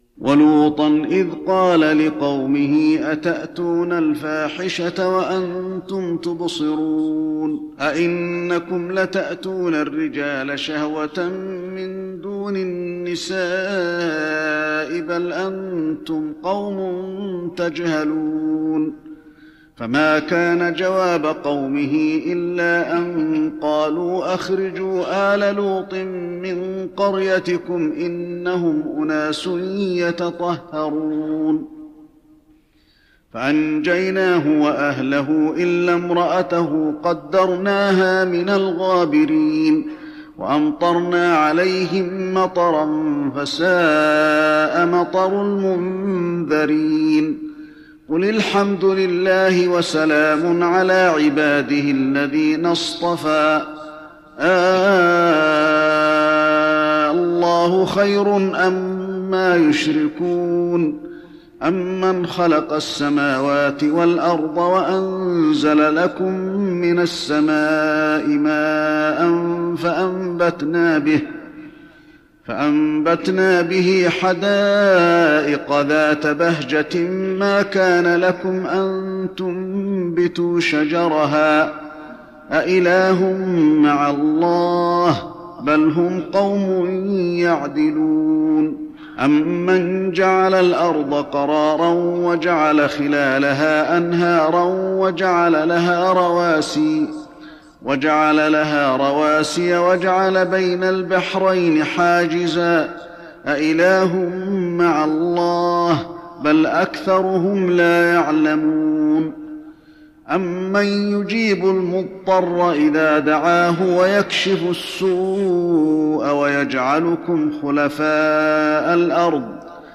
تراويح رمضان 1415هـ من سورة النمل (54-93) والقصص (1-75) Taraweeh Ramadan 1415H from Surah An-Naml and Al-Qasas > تراويح الحرم النبوي عام 1415 🕌 > التراويح - تلاوات الحرمين